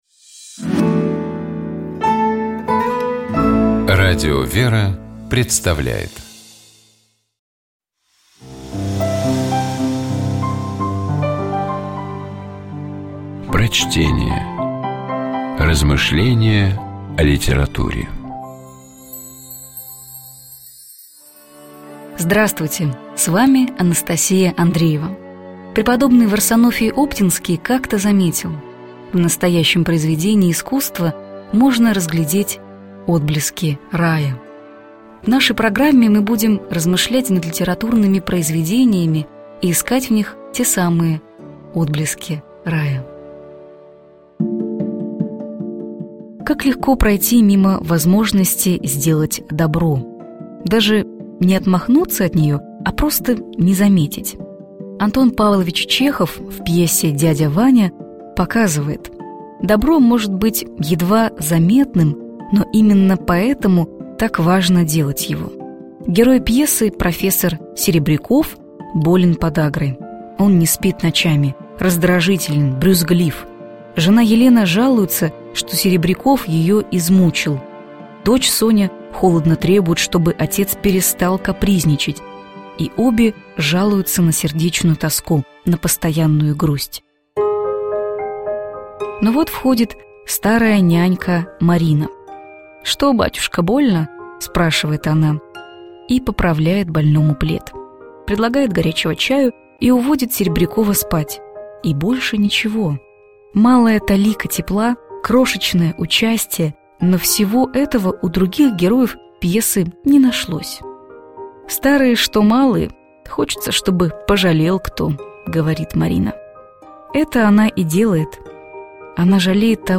Prochtenie-A_-Chehov-Djadja-Vanja-Kroshechnoe-teplo.mp3